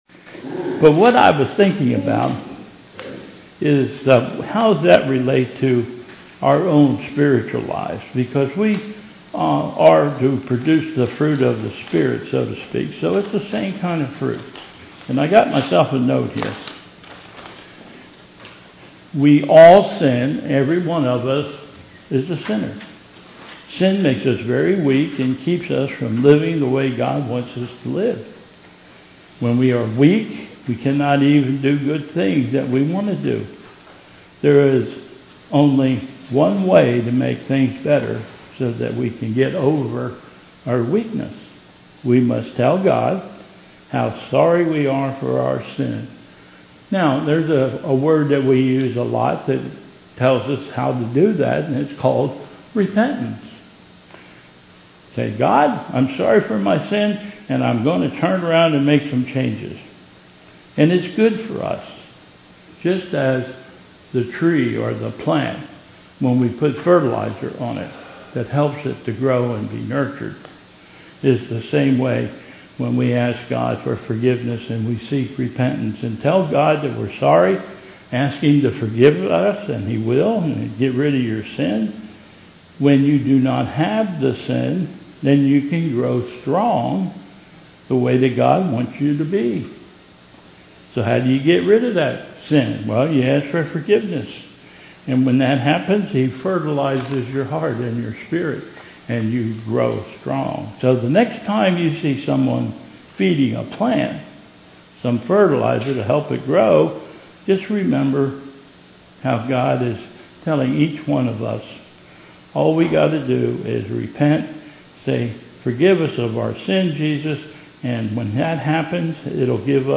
Bethel Church Service
Youth Message